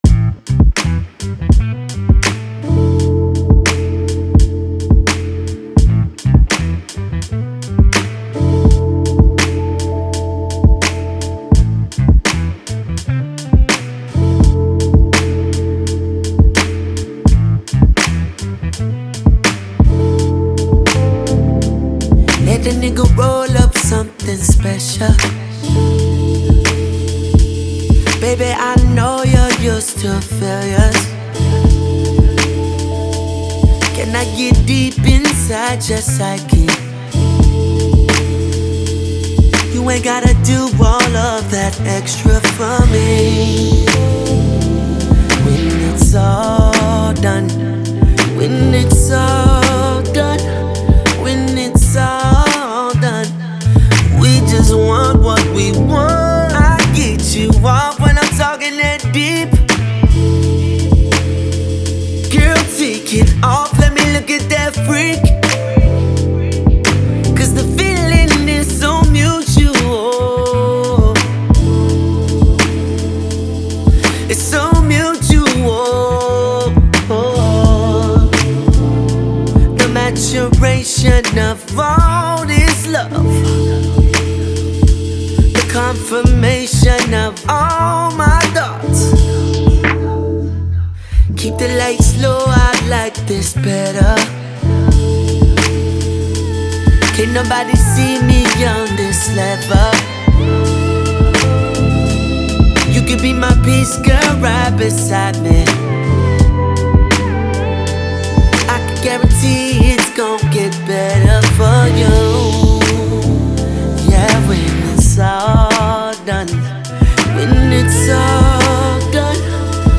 American veteran singer and dancer